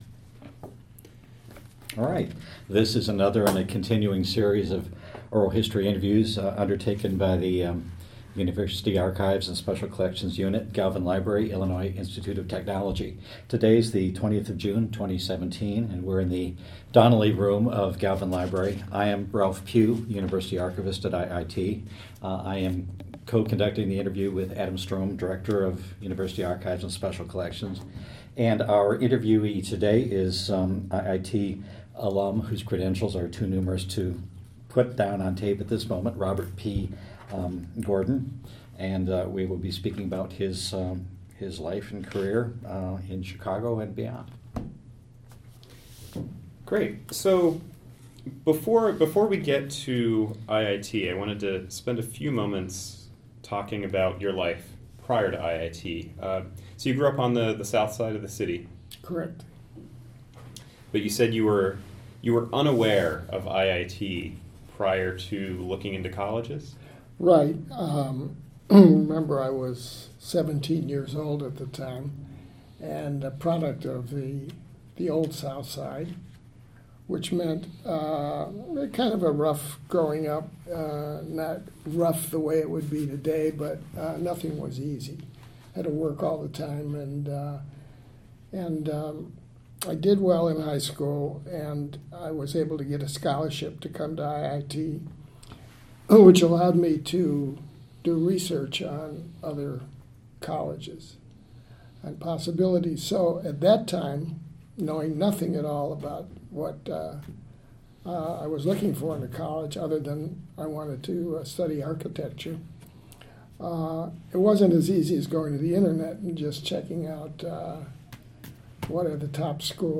Type Interview File type audio/wav Subject Illinois Institute of Technology.